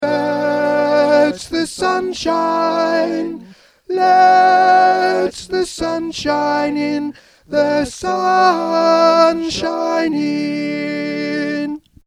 Let The Sunshine In Soprano 2 Voice | Ipswich Hospital Community Choir